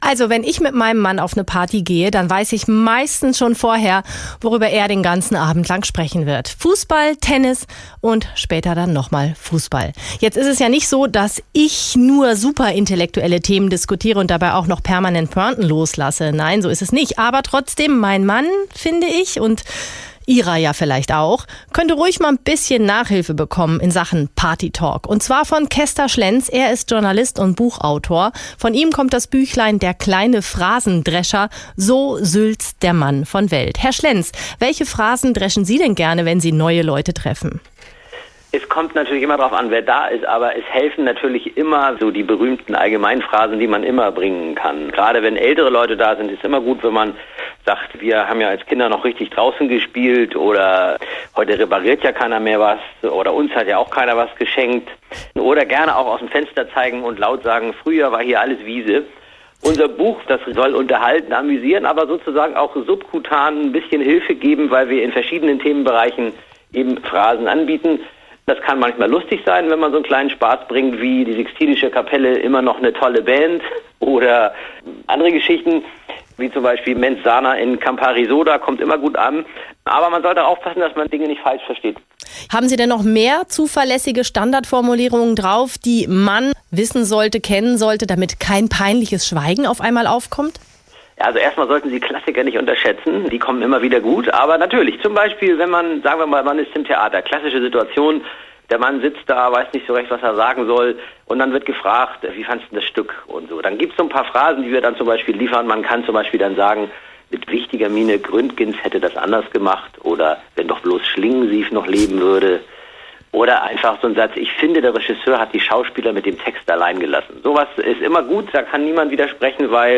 Interview-Phrasen-dreschen.mp3